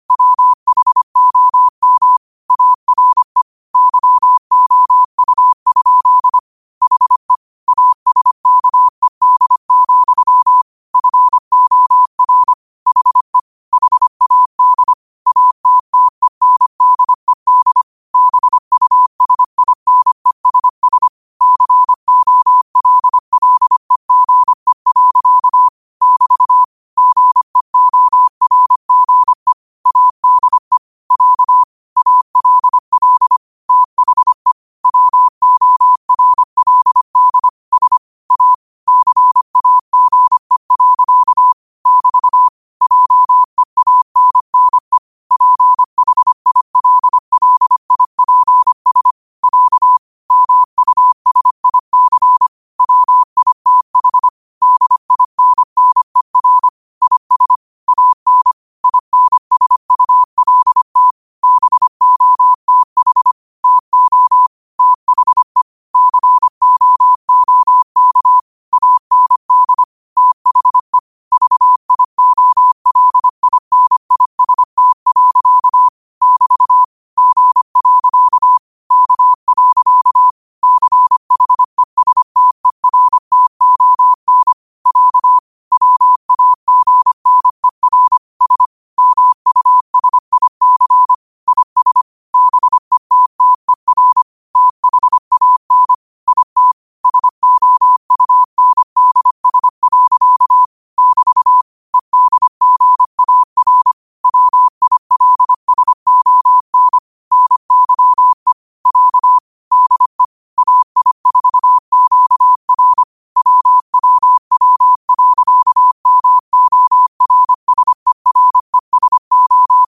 25 WPM morse code quotes for Sat, 16 Aug 2025 by QOTD at 25 WPM
Quotes for Sat, 16 Aug 2025 in Morse Code at 25 words per minute.